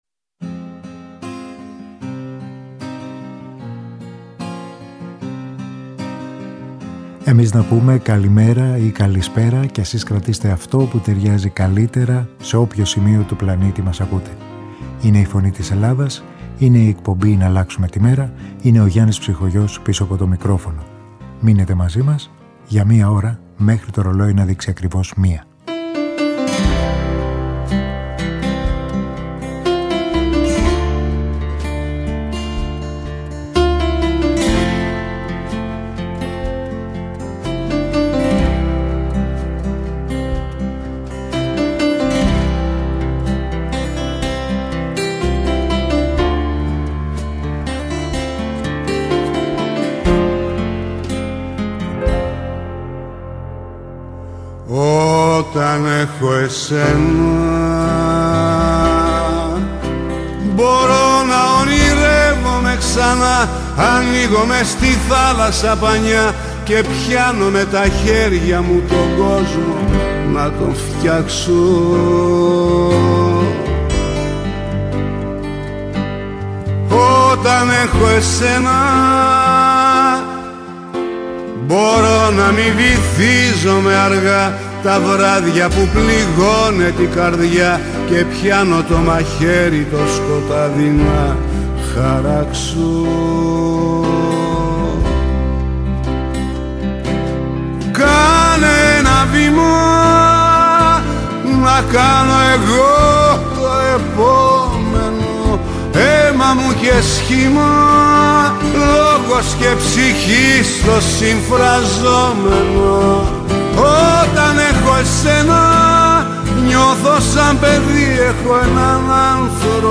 Στην αρχή μιας νέας μέρας με μουσικές
Μουσική